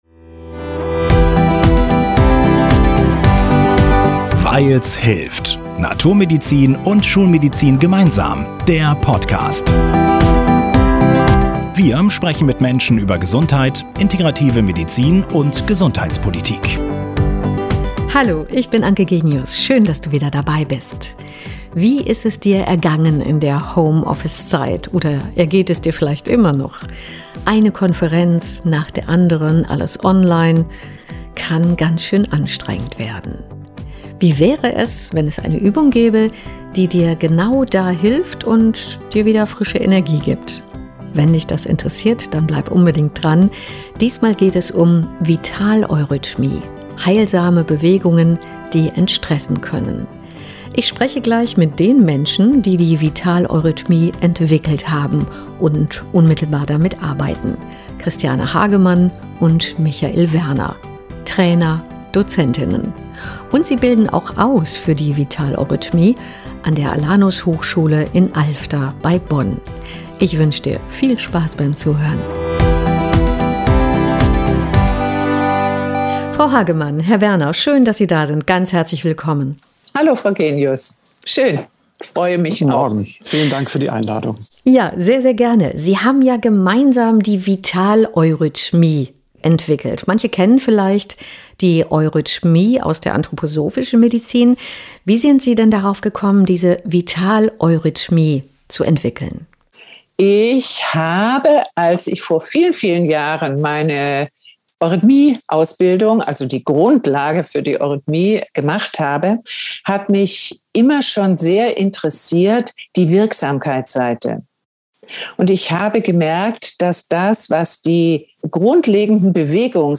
Mehr zu unseren Interviewpartner*innen und weitere Informationen